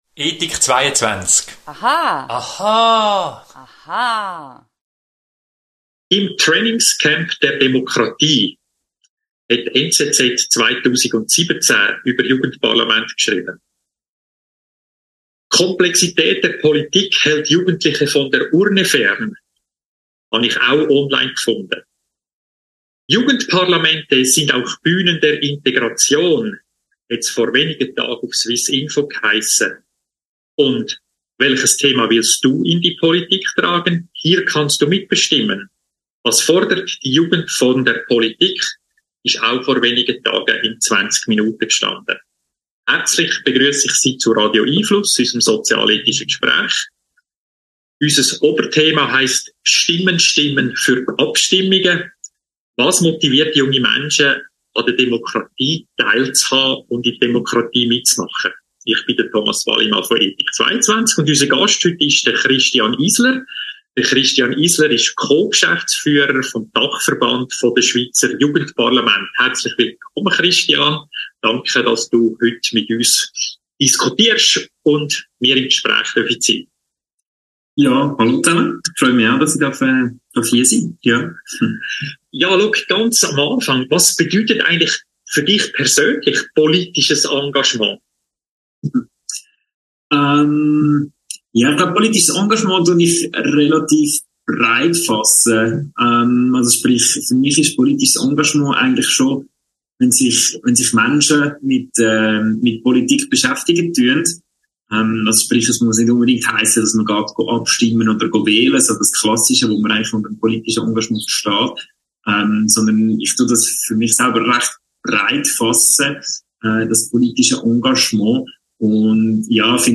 Radio🎙einFluss findet jeden Mittwochabend von 18.30 - 19 Uhr statt.